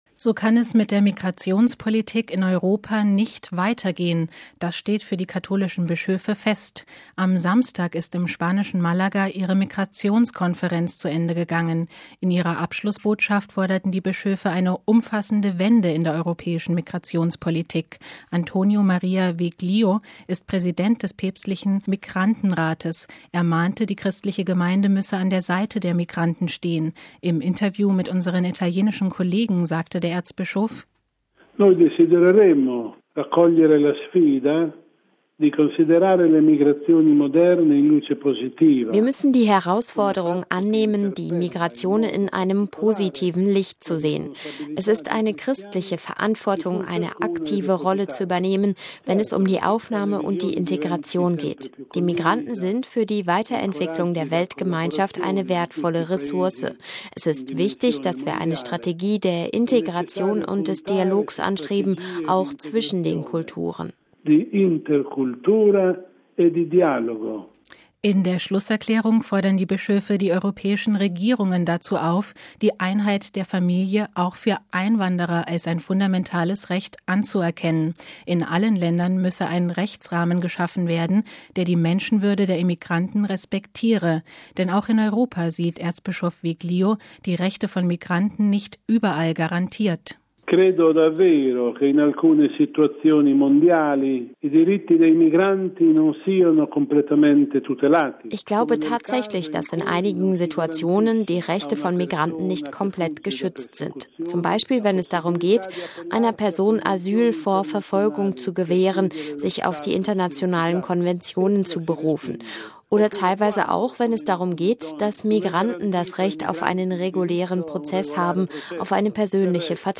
Er mahnte, die christliche Gemeinde müsse an der Seite der Migranten stehen. Im Interview mit unseren italienischen Kollegen sagte der Erzbischof: